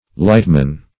Search Result for " lightman" : The Collaborative International Dictionary of English v.0.48: Lightman \Light"man\ (l[imac]t"m[a^]n), n.; pl.